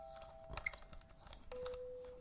描述：一种短促的吱吱声。索尼PCMD50